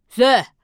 c01_5胖小孩倒数_3.wav